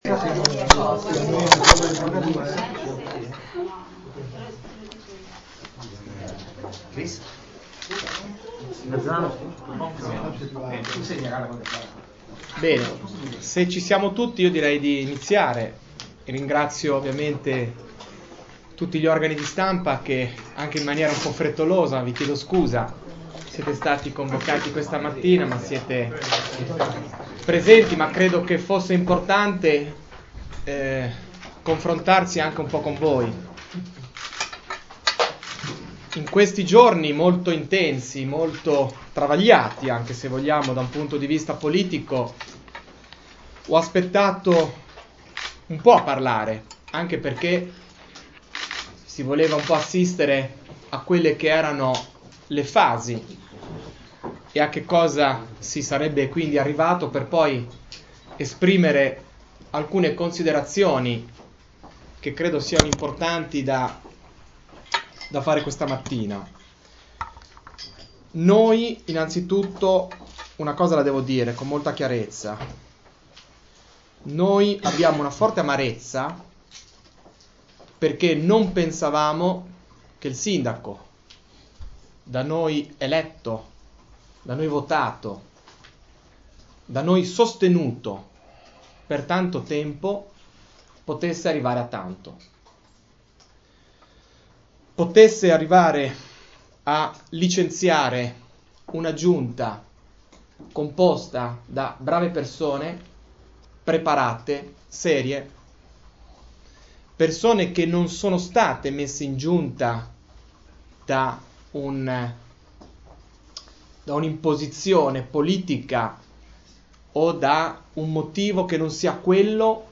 Audio_conferenza_stampa_pdl.MP3